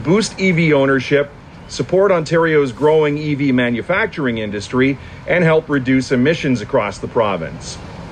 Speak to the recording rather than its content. They gathered at the westbound Trenton ONroute to announce that fast chargers had been installed at all ONroutes, ahead of the summer tourist season.